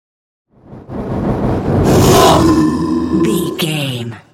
Whoosh large creature
Sound Effects
ominous
eerie
the trailer effect
roar